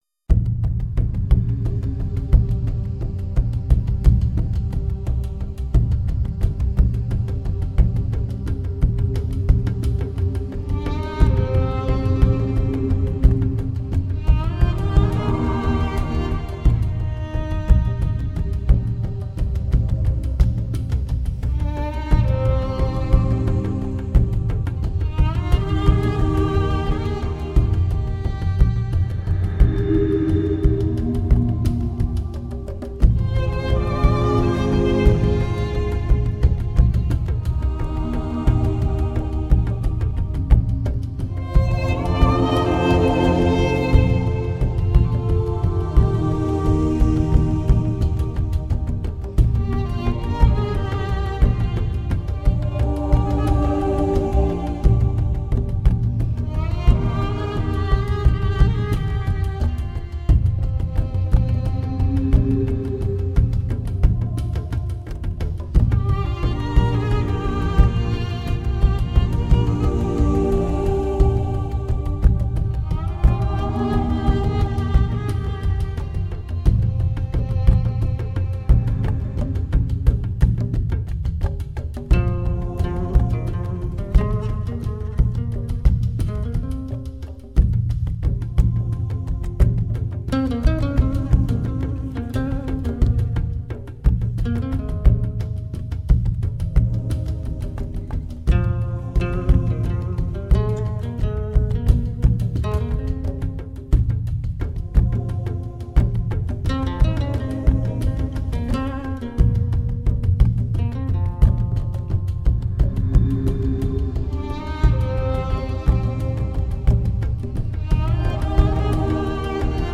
他不但采用了电脑音乐制作，配上了让人有亲近感的人声
稻香村的呢哝喃语，统万城的恢弘大气，七星窟的诡异与恐怖，九天的飘逸与十地的步步杀机。